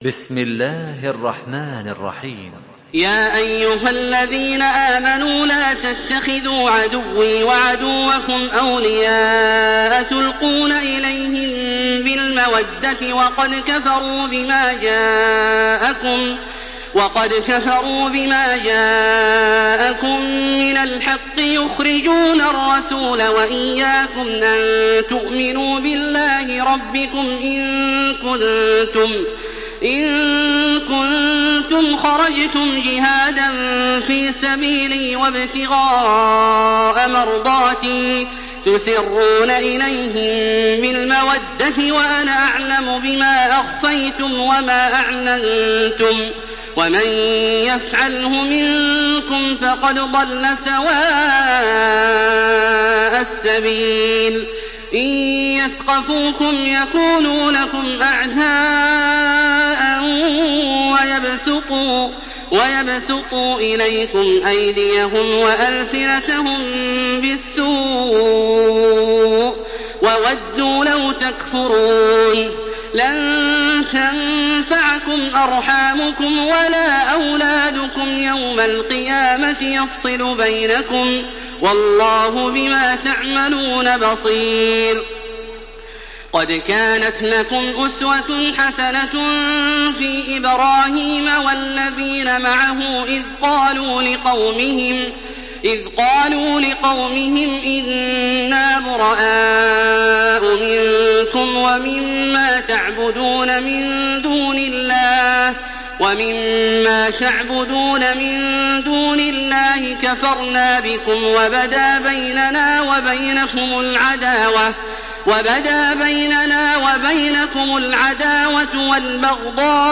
Makkah Taraweeh 1427
Murattal